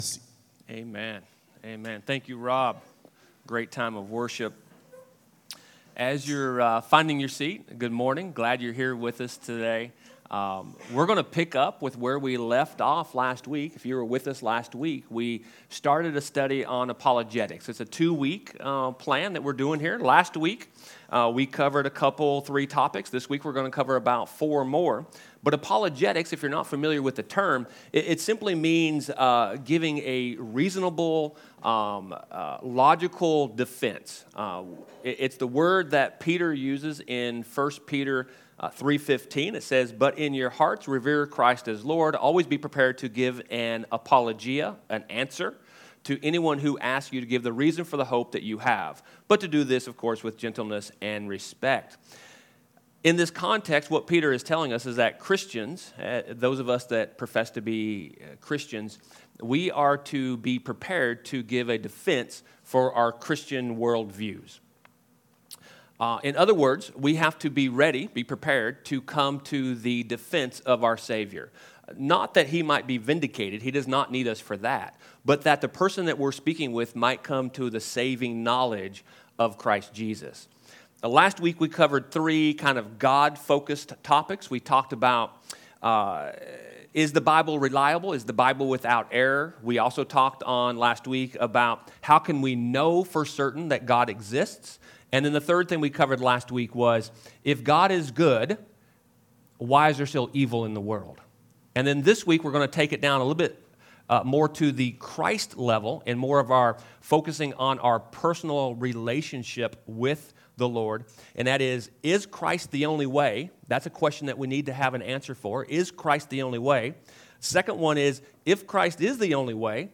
Sermons | The Table Fellowship